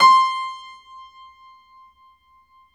53d-pno18-C4.wav